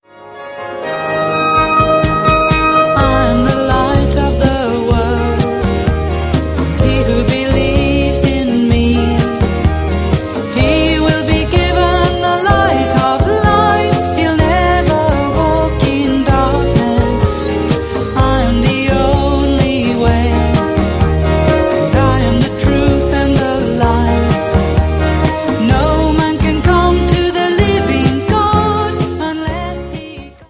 • Sachgebiet: Pop